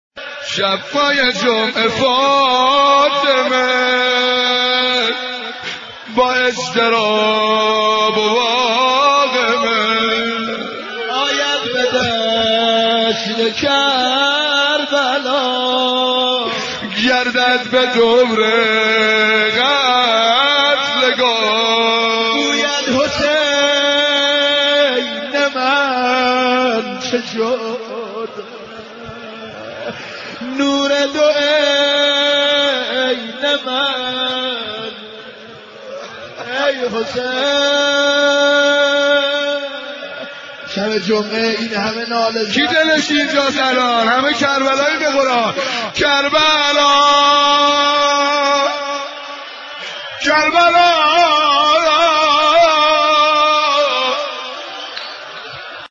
فایل مداحی مورد اشاره پیوست شد.